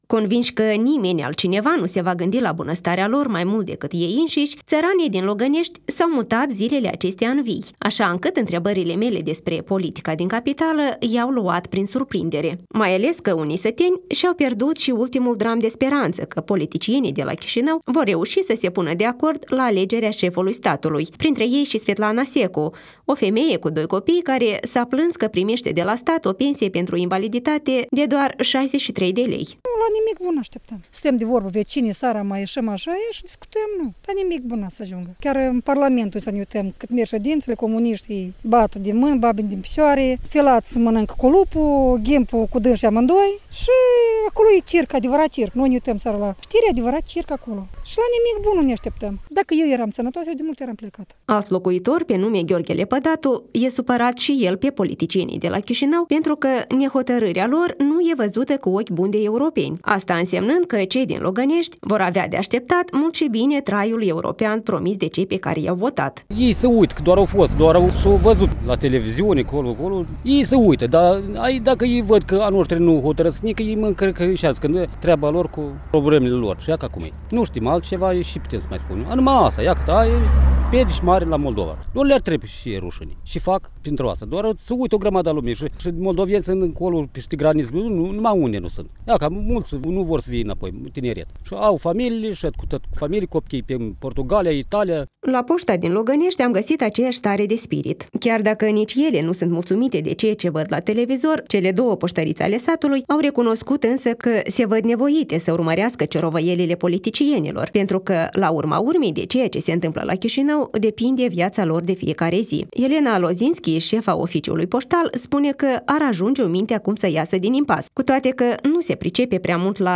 Şi alţi săteni s-au dat cu părerea în faţa microfonului sugerîndu-le politicienilor că aşteaptă cu totul altceva de la ei decît alegeri anticipate.